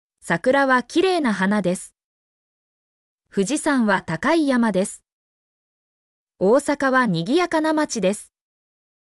mp3-output-ttsfreedotcom-42_bpTKuvF8.mp3